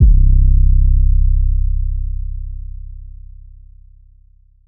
808_Oneshot_Zillow_C.wav